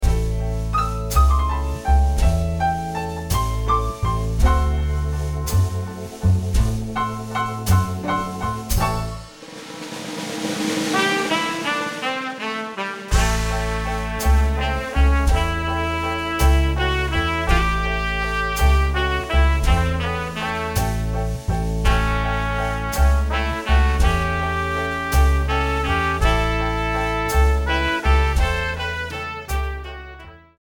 55 BPM